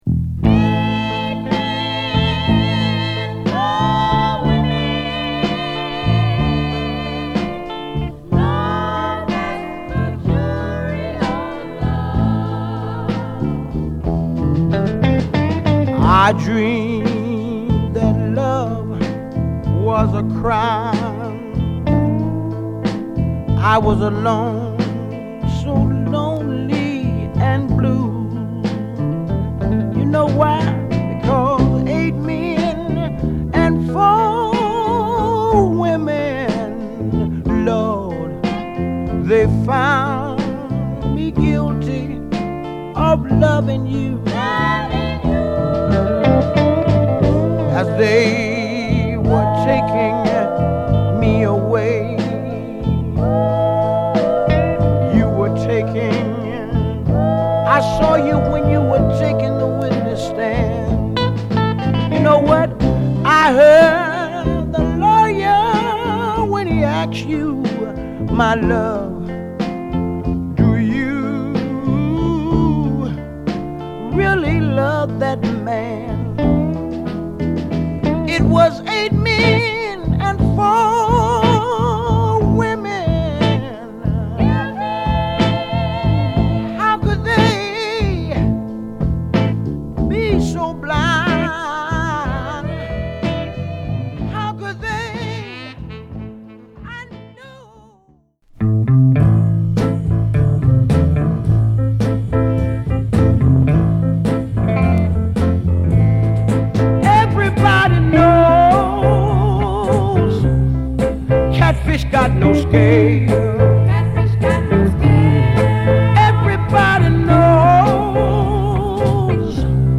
メロウなR&B〜バラードを中心に、ノリ良くポップなR&Bまでを披露した1枚。